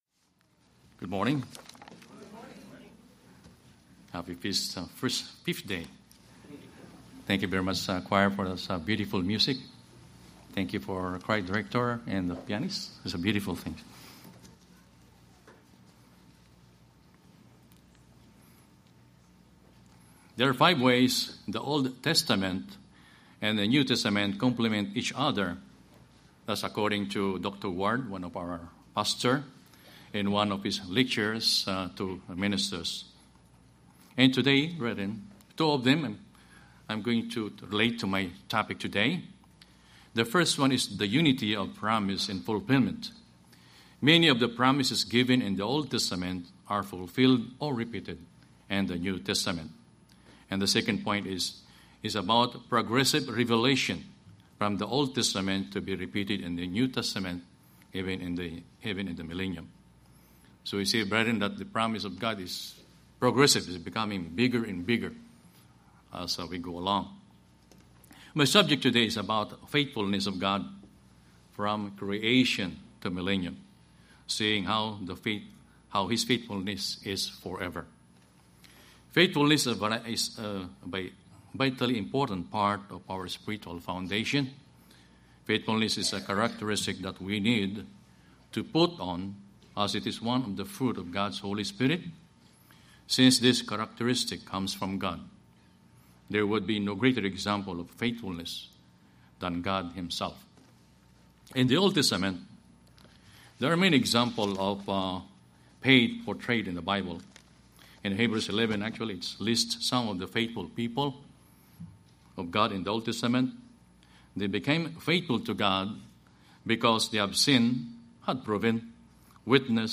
This sermon was given at the Ocean City, Maryland 2015 Feast site.